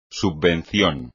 À peu près la prononciation est comme ça : "subvenir" /SUB-be-NIR/, "subvención" /SUB-ben-CION/ (il y a deux syllabes toniques à cause du préfixe "sub-").